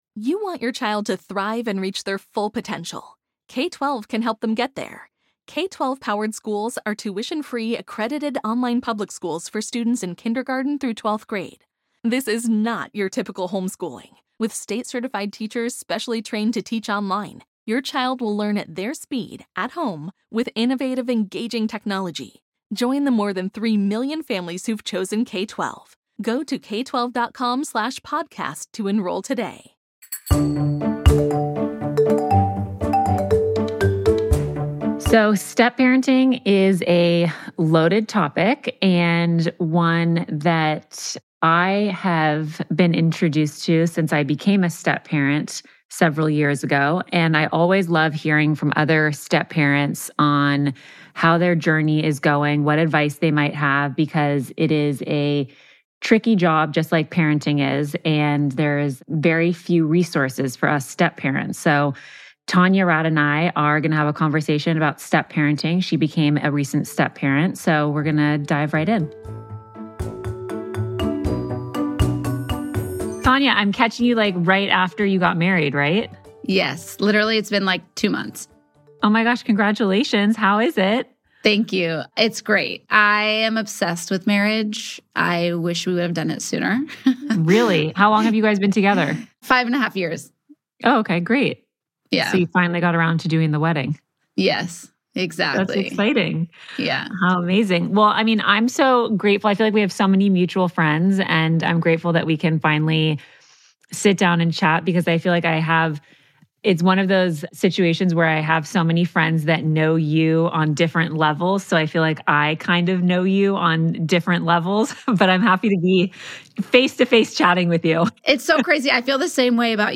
Radio and TV personality, author, and podcast host Tanya Rad joins for a deeply personal and refreshingly honest conversation about step-parenting—a role both of us have stepped into and are still learning to navigate.